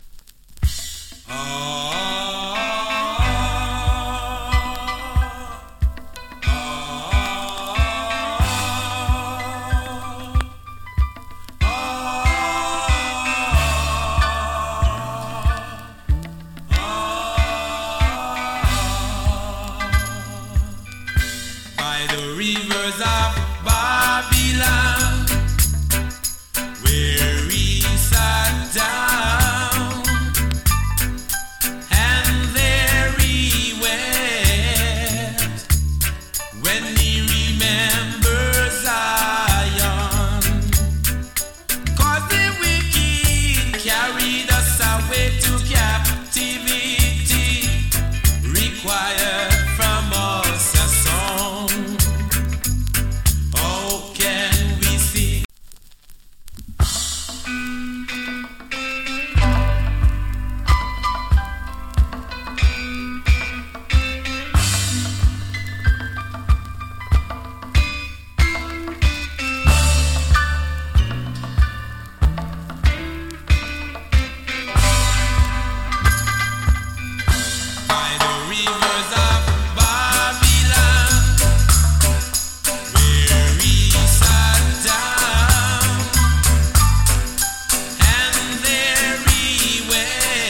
A：VG(OK) / B：VG+ ＊スリキズ少々有り。チリ、ジリノイズ少し有り。
ROOTS DEE-JAY